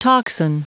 Prononciation du mot tocsin en anglais (fichier audio)
Prononciation du mot : tocsin